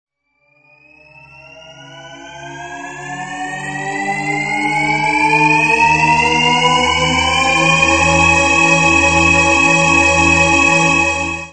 効果音
紙を破る音、ゴクゴク飲む音、トイレの音など・・・、ありそうでなさそな音40点を集めたゲーム用効果音素材集！